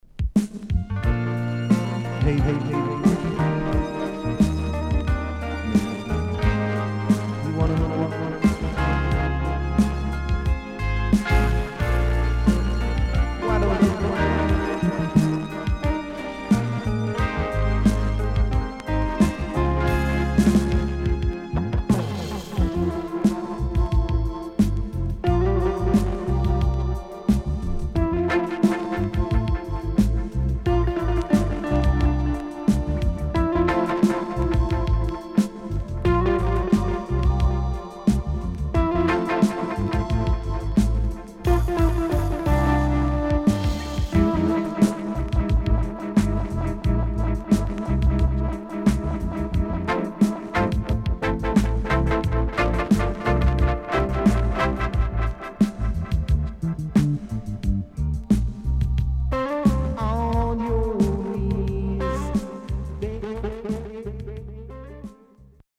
Nice Lovers Vocal & Dubwise
SIDE A:少しチリノイズ入ります。